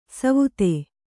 ♪ savute